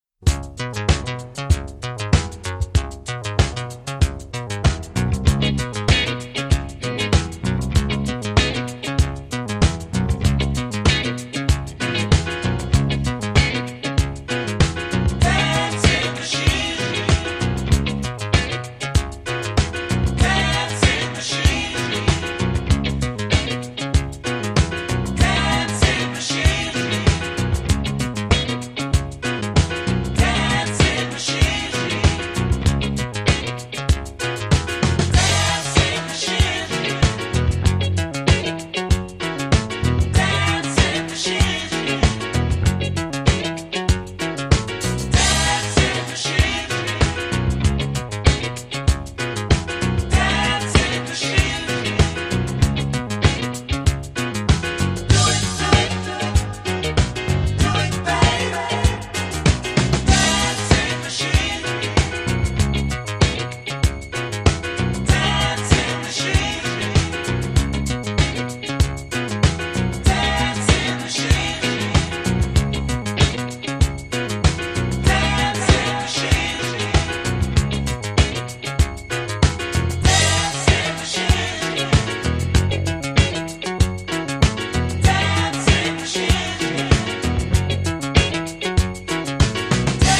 夢見心地なミッドテンポ・ブギー